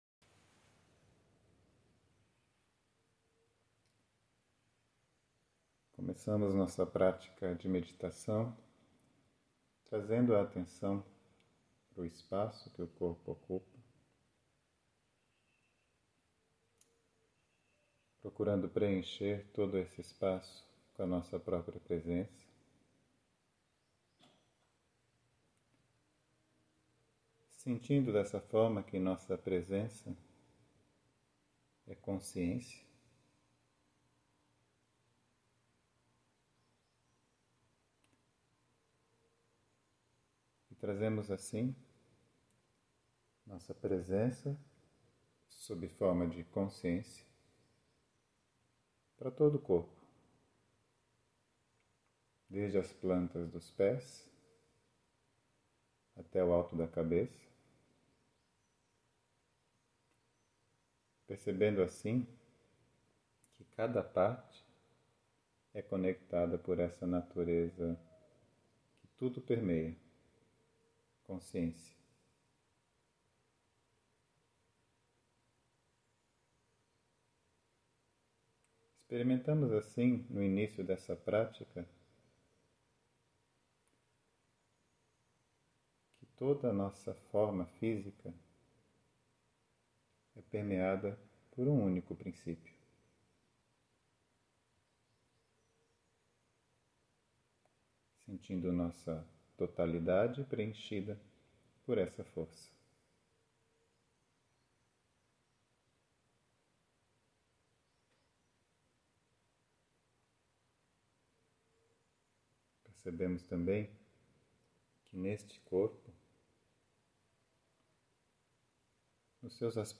Meditação Guiada – Introdução
meditacao-guiada-01.mp3